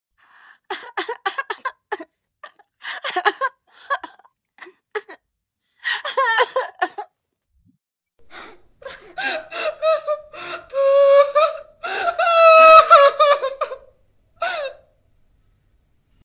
Effetto sonoro: Donna che piange